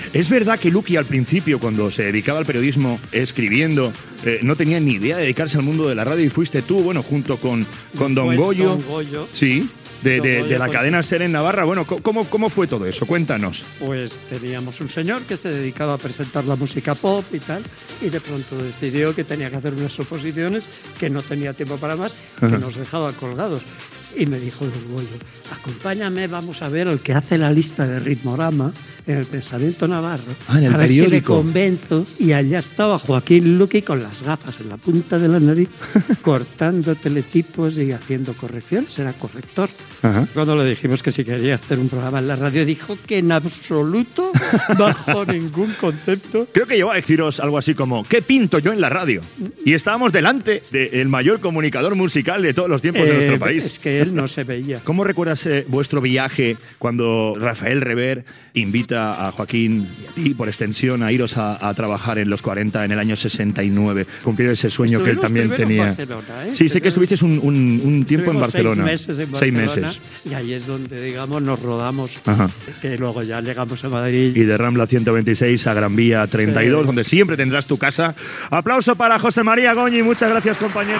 Fragments d'una entrevista